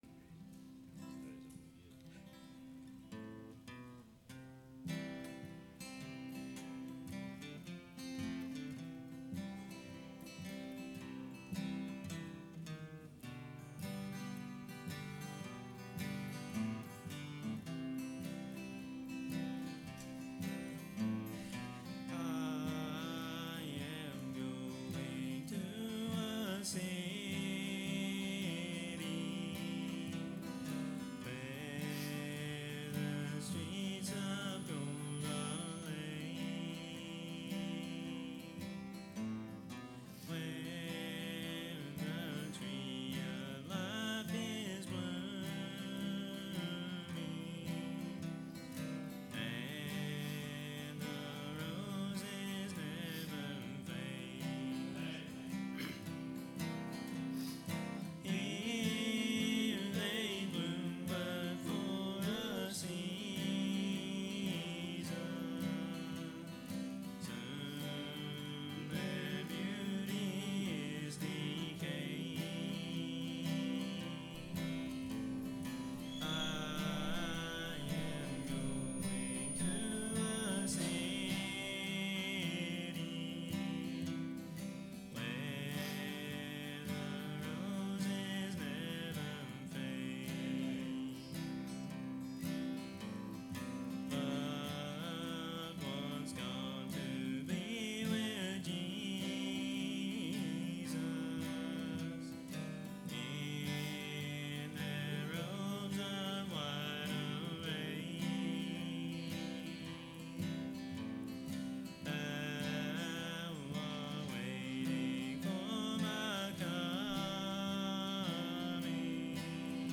Sermons | Richardson's Cove Baptist Church